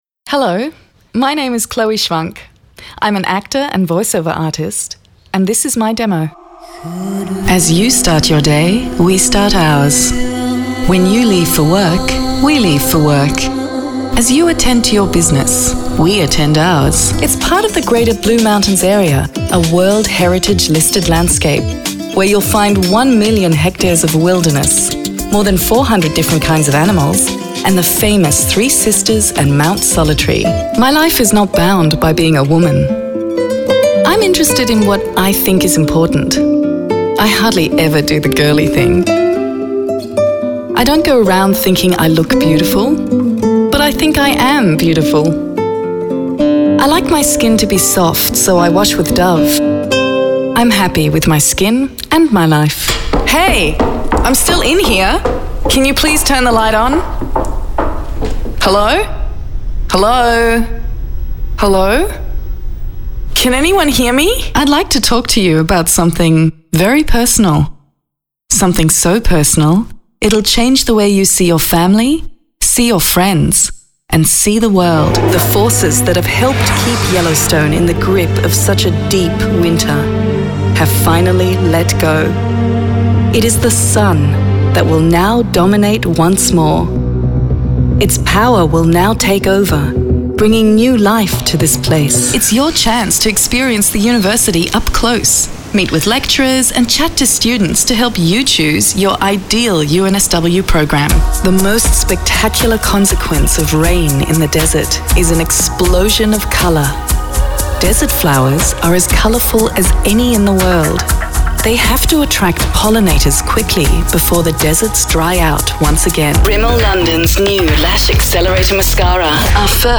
Sydney based actor & VO artist with a warm, authentic and educated voice.
Compilation (Main) Demo - English
English - Australian
Middle Aged